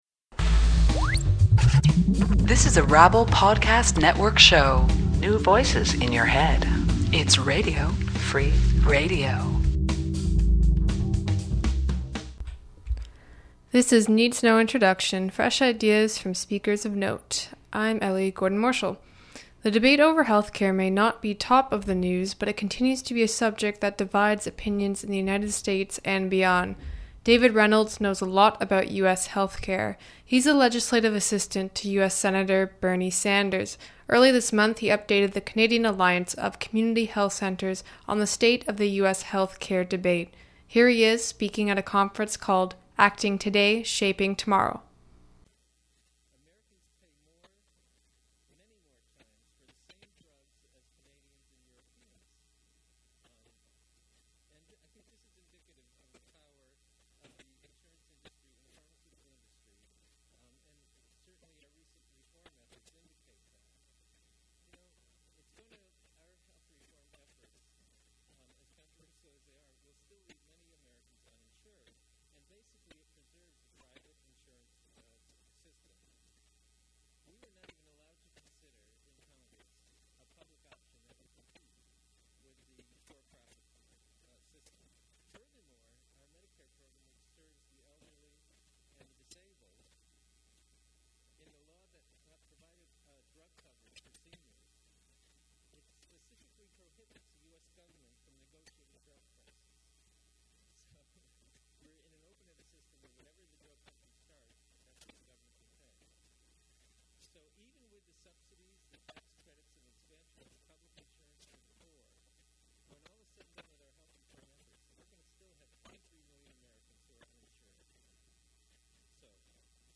'Acting Today, Shaping Tomorrow' was an international community health conference focused on improving population health and increasing the sustainability of health-care systems.
It ran June 9 and 10, 2011 at the Westin Harbour Castle, Toronto, Canada.